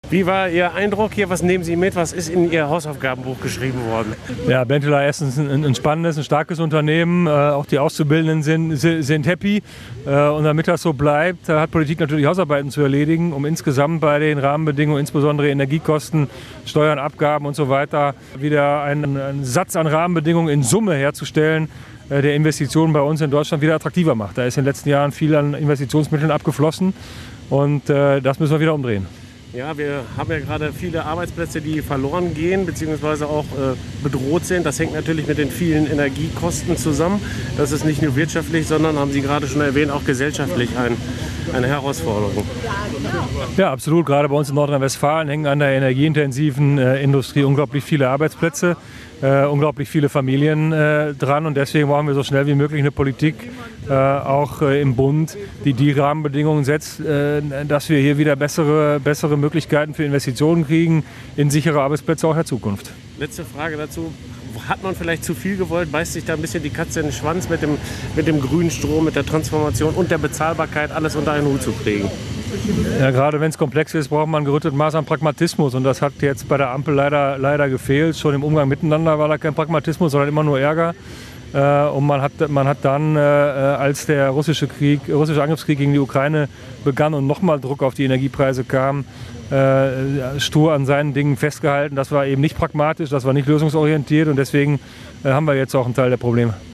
statement-mp-wuest-nach-besuch-in-dinslaken-1.mp3